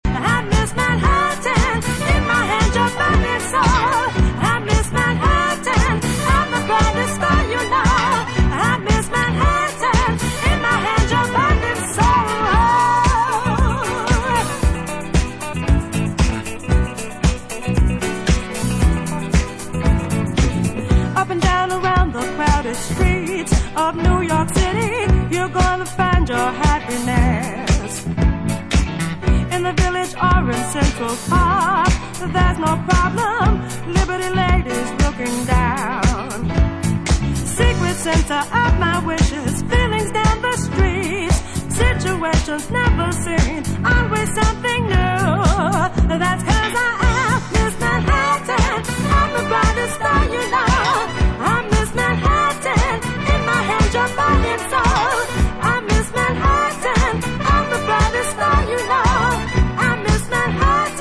Italodisco re-issue. Recorded in Italy 1981